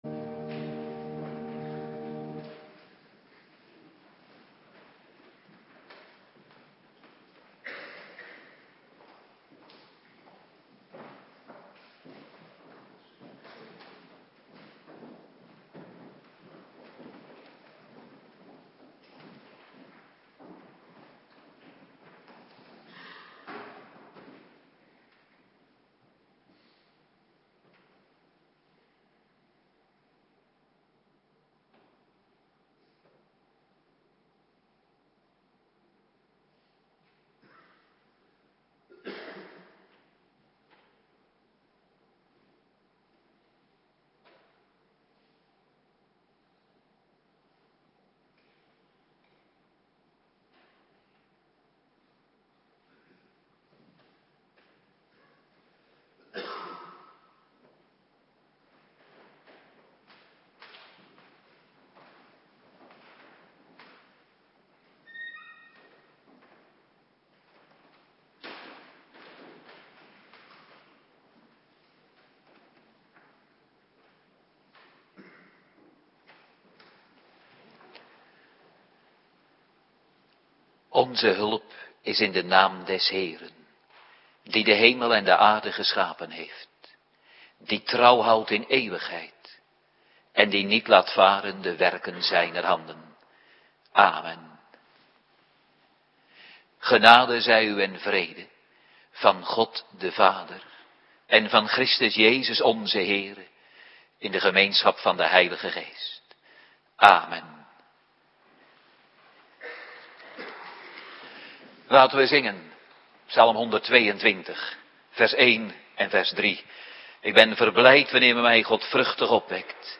Woensdagavonddienst
19:30 t/m 21:00 Locatie: Hervormde Gemeente Waarder Agenda